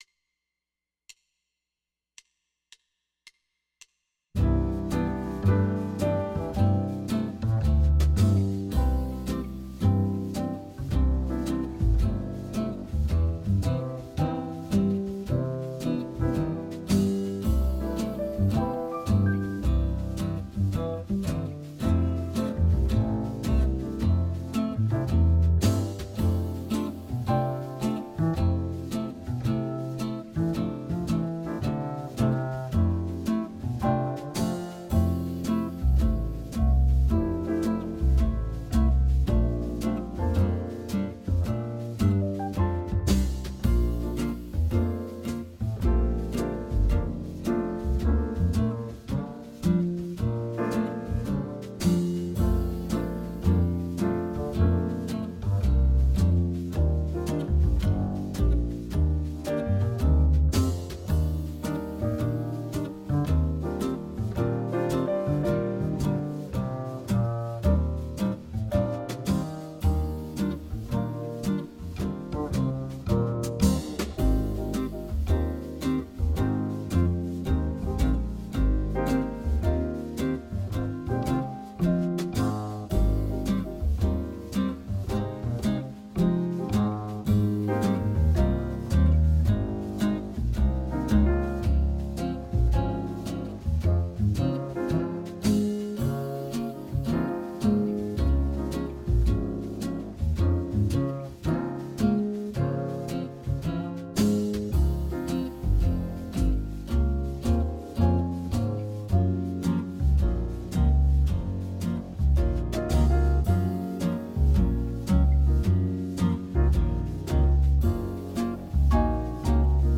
96-Bar drill